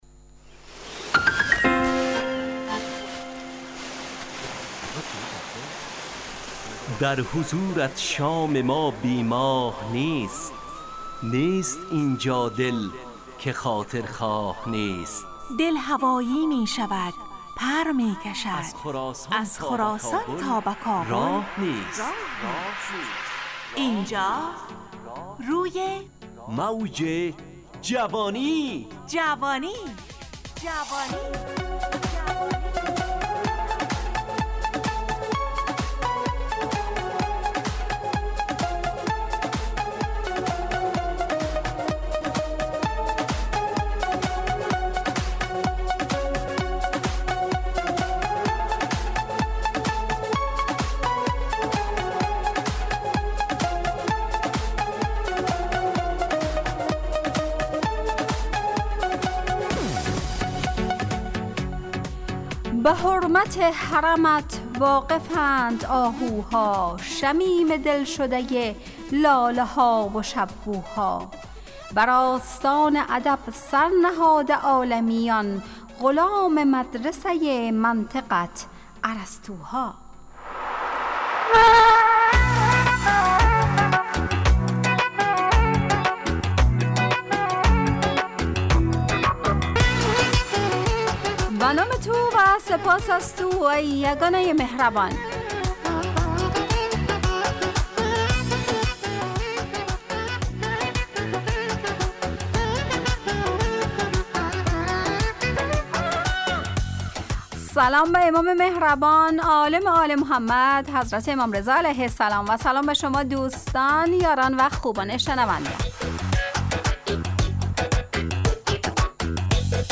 روی موج جوانی، برنامه شادو عصرانه رادیودری.
همراه با ترانه و موسیقی .